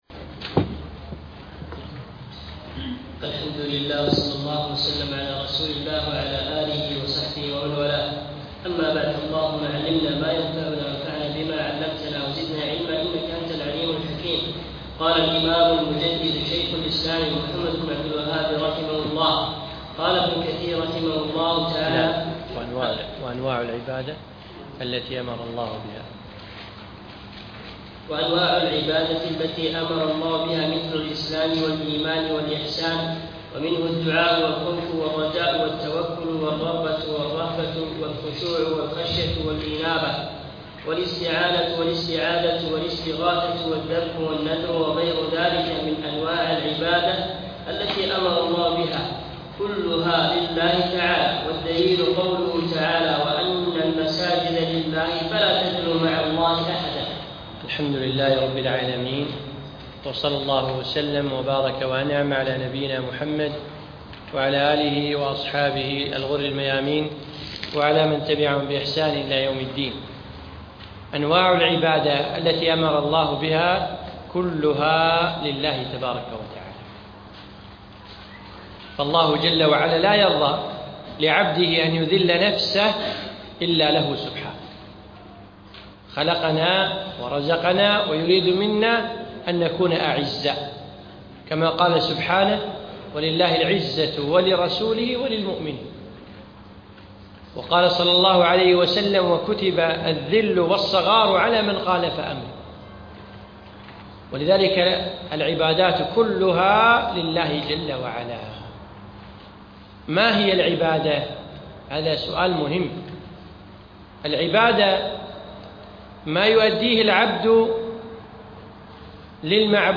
أقيمت الدورة في مسجد عبدالله الحمادي بمنطقة المهبولة من 6 1 إلى 8 1 2015
الدرس الثاني